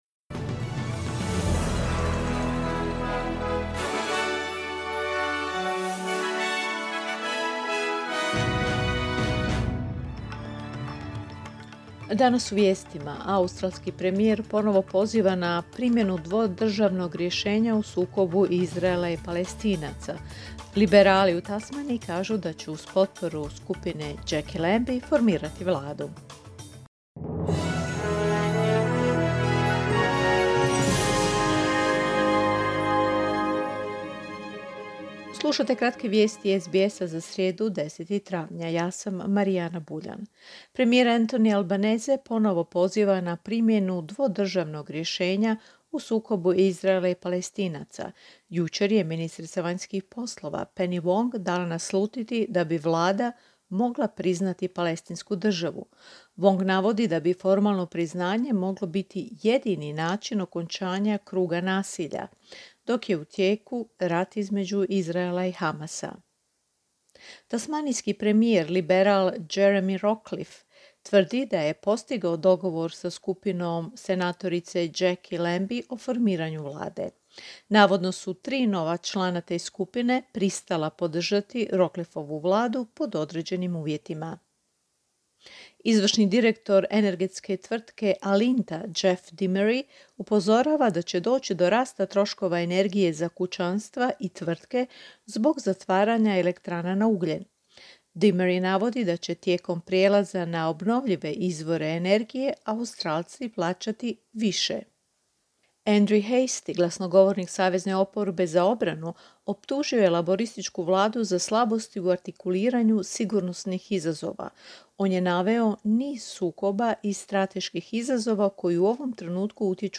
Vijesti SBS-a na hrvatskom jeziku.
Vijesti radija SBS.